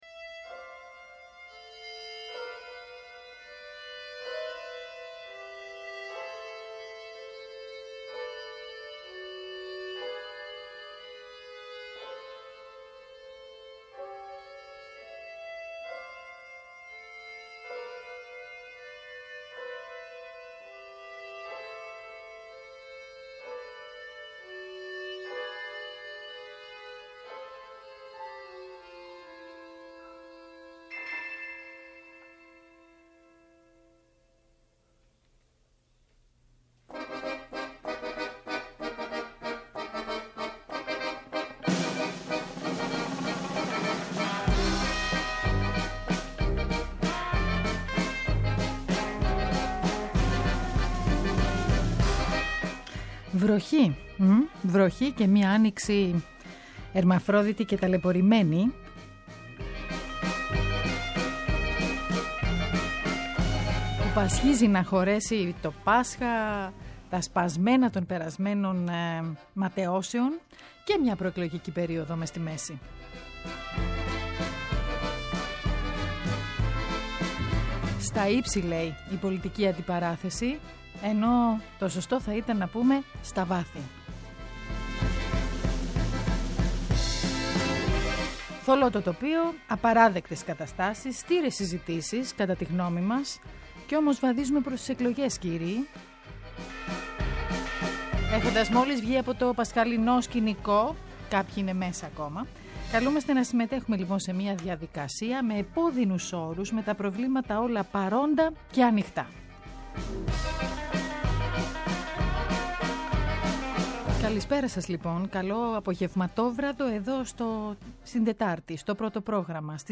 Καλεσμένος στη σημερινή εκπομπή ο μουσικός, τραγουδοποιός, συνθέτης, ερμηνευτής Θανάσης Παπακωνσταντίνου.